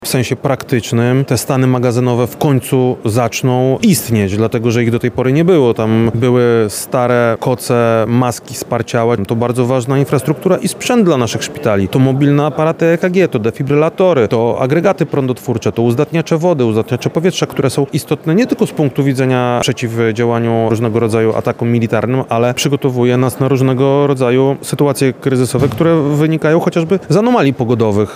Lubelskie jest liderem w wykorzystaniu środków na poprawę bezpieczeństwa w regionie – powiedział Radiu Lublin wojewoda lubelski, Krzysztof Komorski.